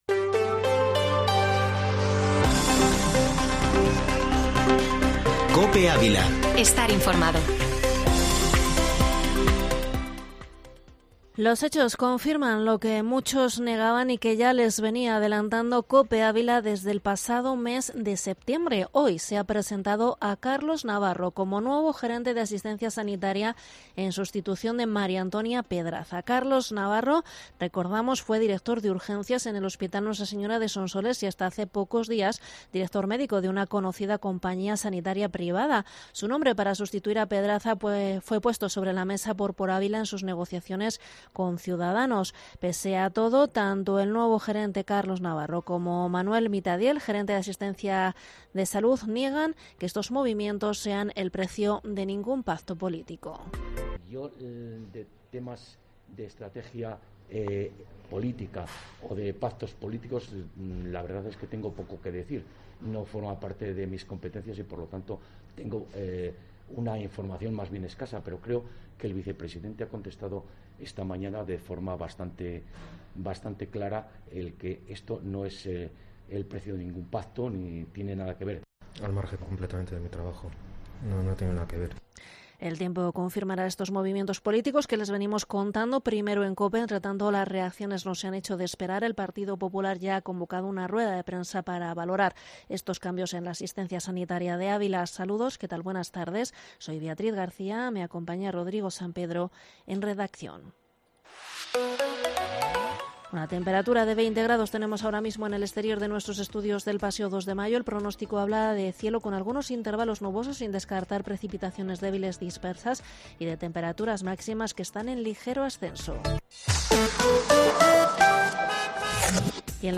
informativo Mediodía COPE ÁVILA 18/10/2021